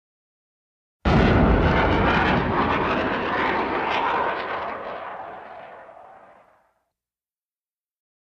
WEAPONS - VARIOUS SURFACE TO AIR MISSILE: EXT: Launch and long rocket trail fade, distant.